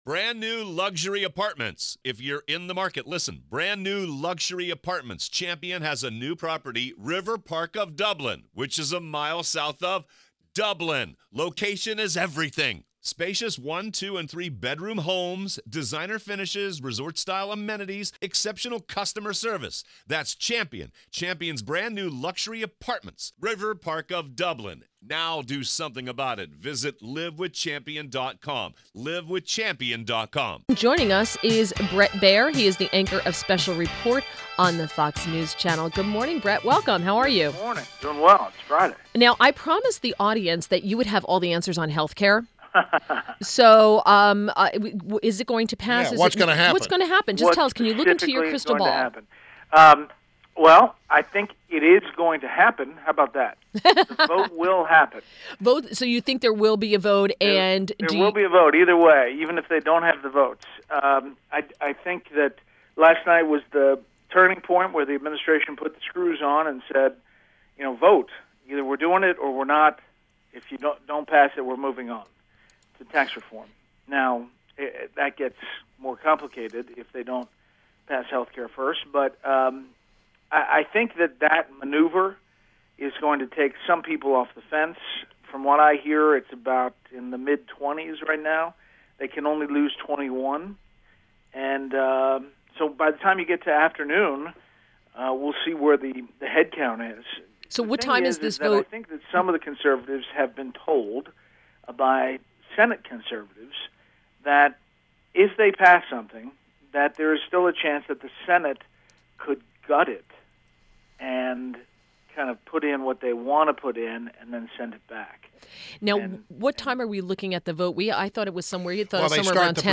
INTERVIEW – BRET BAIER – Anchor of Special Report on Fox News Channel